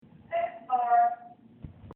The elevators in our building have a curious recorded floor
The female voice has an unplaceable accent: nasal, snooty-almost-schoolmarmish, vaguely Canadian. Probably the result of a focus group on pleasing intonation gone wrong.
sixth_floor.mp3